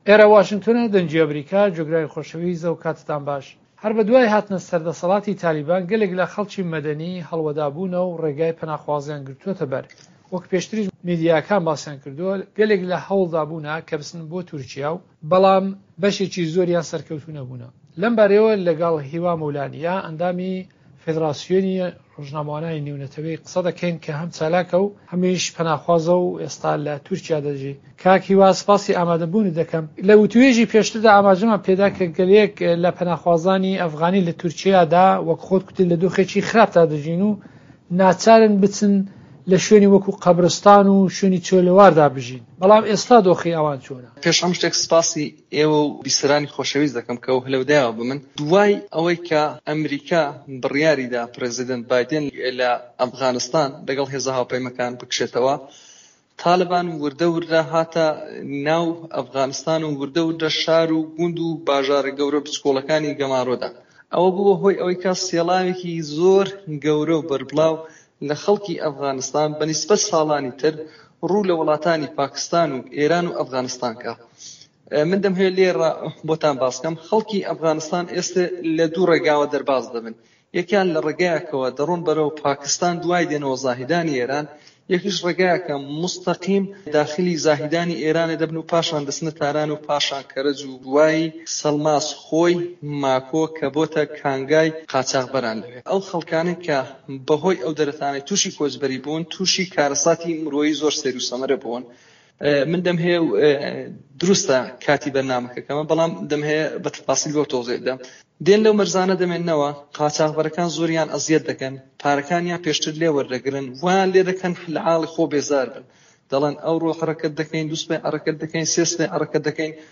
وتووێژەکان